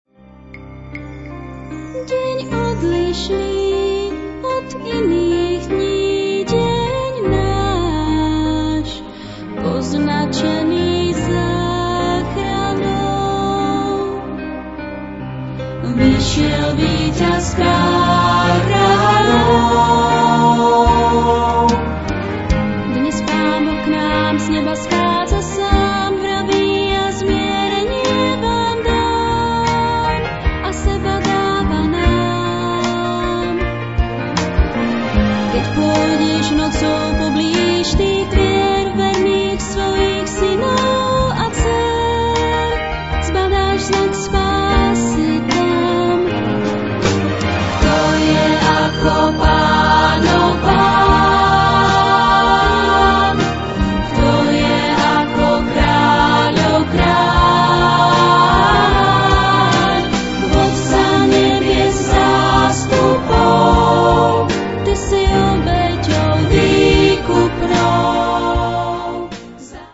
pôvodný slovenský muzikál